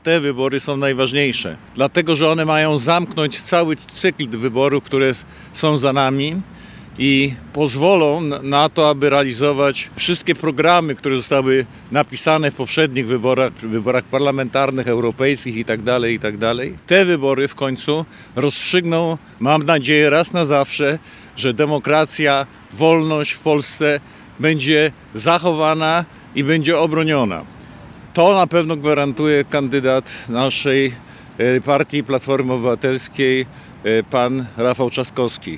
Podczas dzisiejszej konferencji prasowej w Szczecinie, Paweł Bartnik, przewodniczący Rady Miasta, zwracał uwagę na stawkę tegorocznych wyborów.